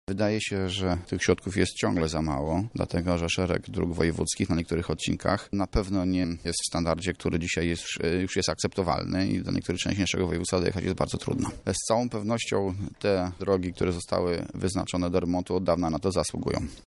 O potrzebie zwiększenia funduszy na remonty dróg mówi Andrzej Pruszkowski, wiceprzewodniczący sejmiku.